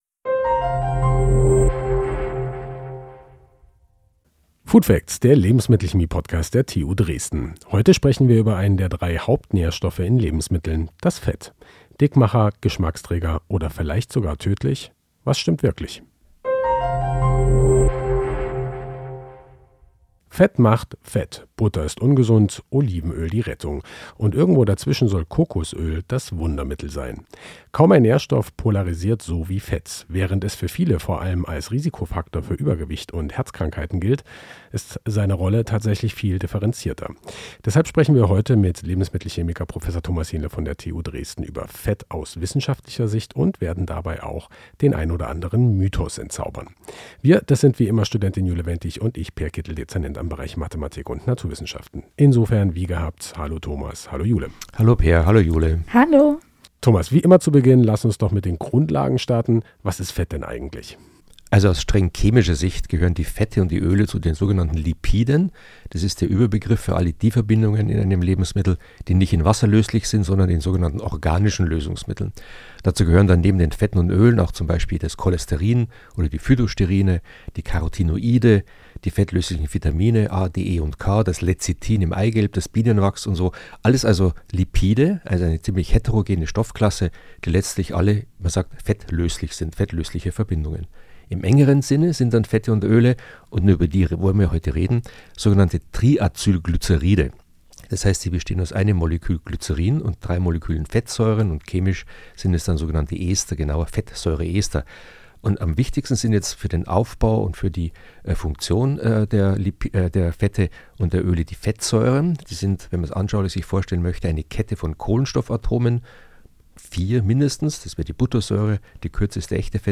Folge 21: Let’s talk about Fett: Strukturen, Wirkungen und Mythen ~ Food Facts – der Lebensmittelchemie-Podcast der TU Dresden Podcast